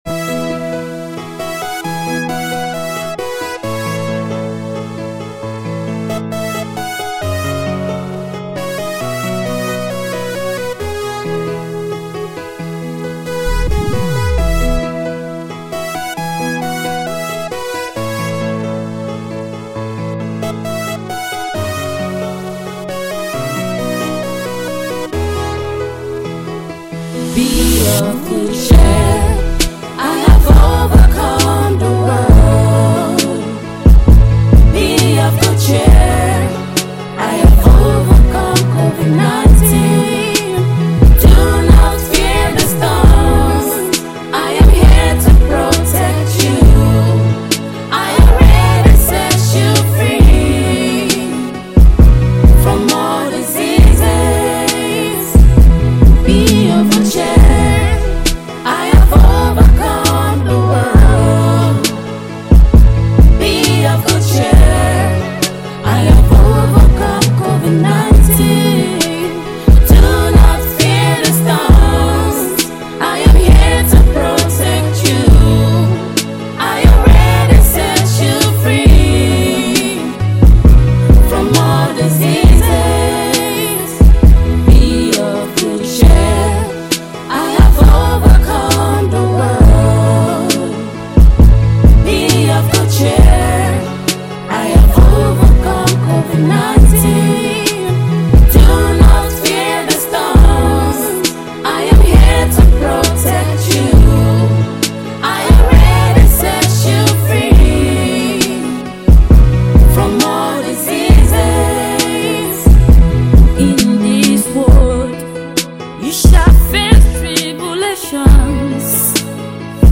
Ghanaian gospel singer and worshiper